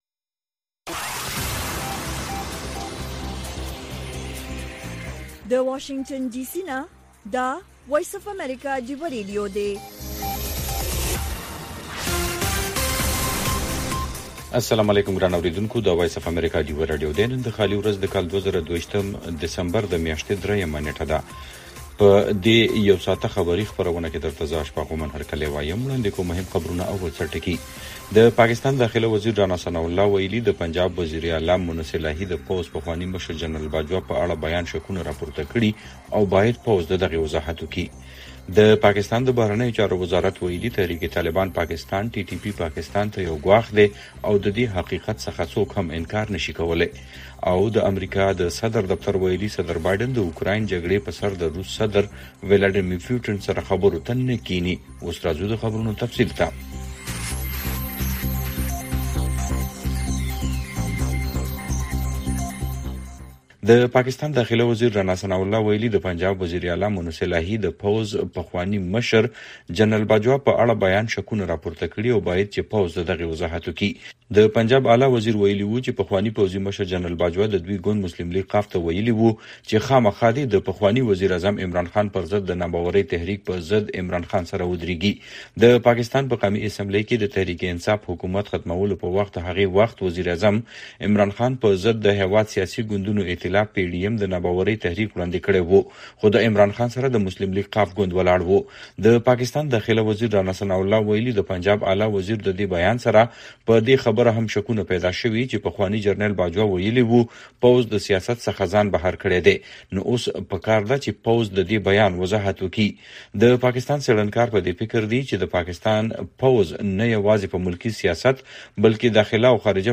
د وی او اې ډيوه راډيو خبرونه چالان کړئ اؤ د ورځې د مهمو تازه خبرونو سرليکونه واورئ.